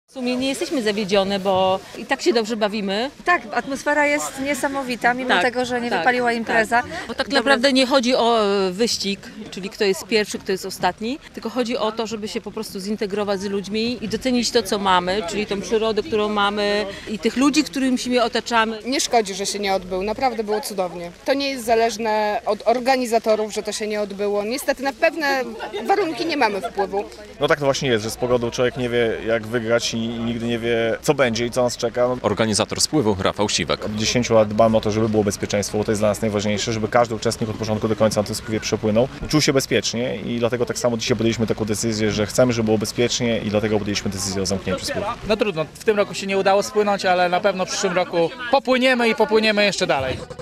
Największy polski spływ kajakowy został odwołany z powodu intensywnych opadów - relacja